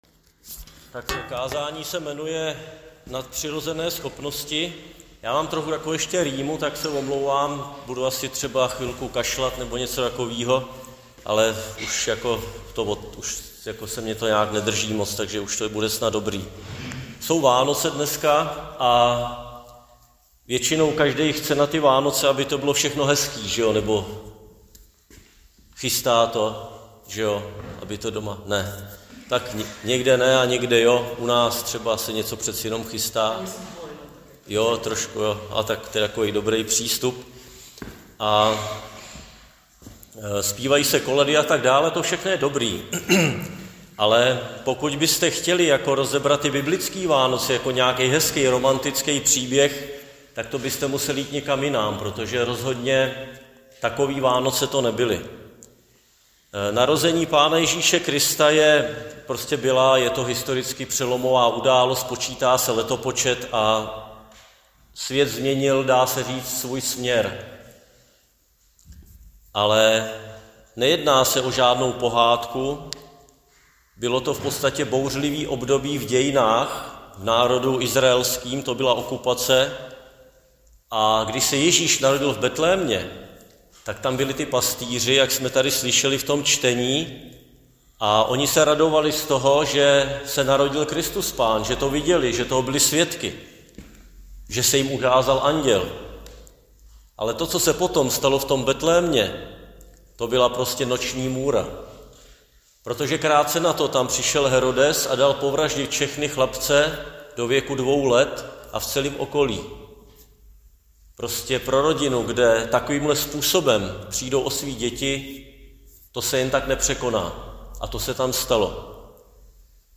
Křesťanské společenství Jičín - Kázání 24.12.2023